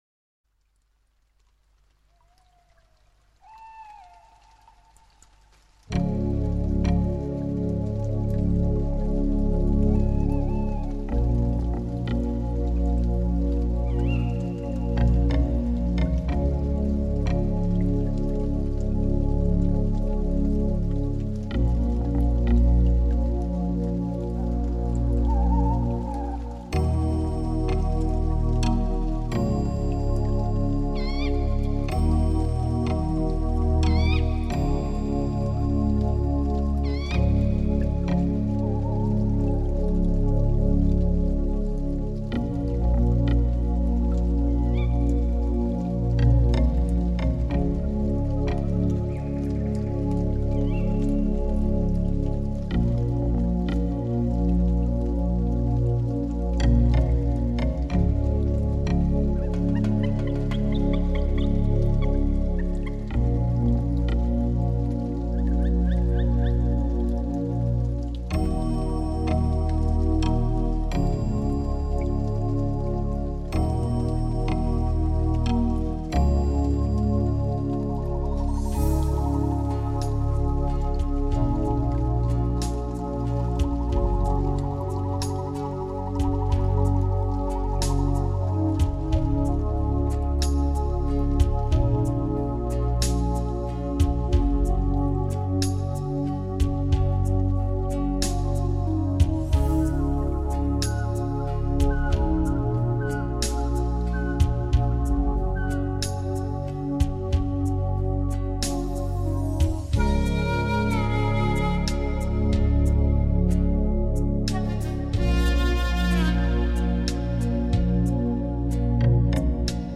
全碟大量的使用了直笛和排萧的吹奏，键盘及敲击乐器等传统南美乐器，加上隐隐的和声，揉出一派纯真的南美风格。